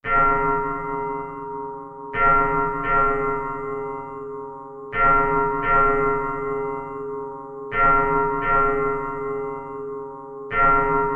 描述：教堂的钟声记录在大街上
Tag: 教堂 街道 宗教 贝尔 现场录音 城市